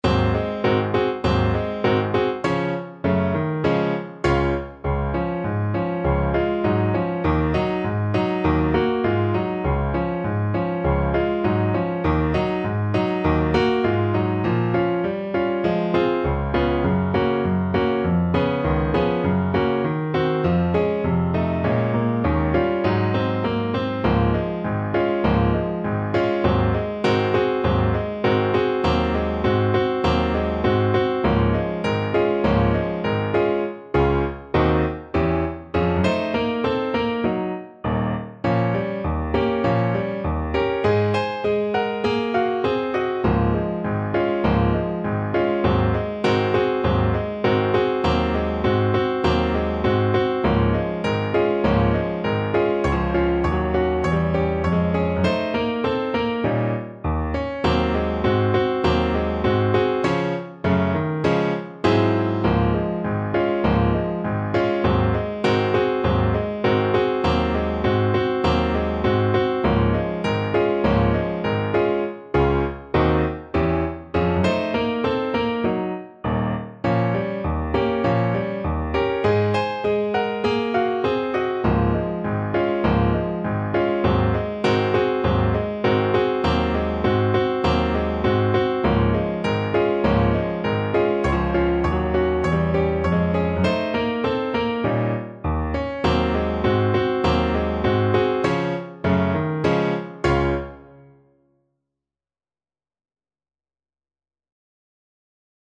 Moderato =c.100